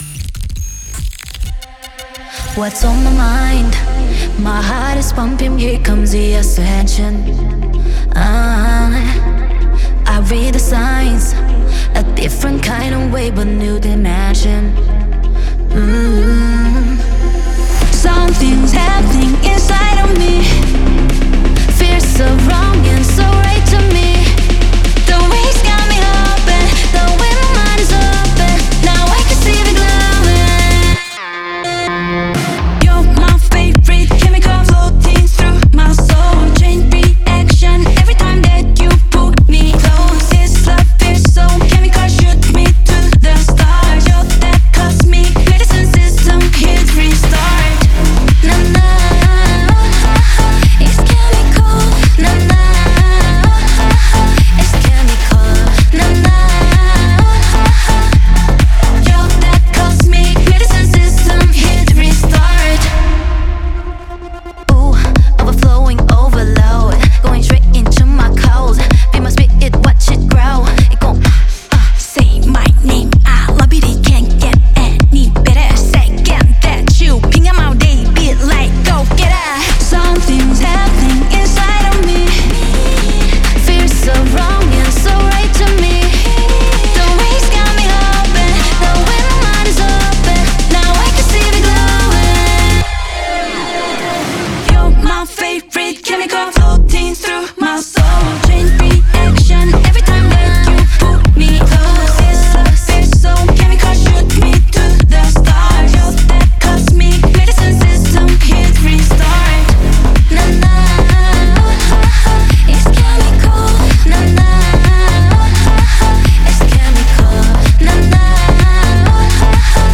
BPM128
Audio QualityPerfect (High Quality)
K-Pop song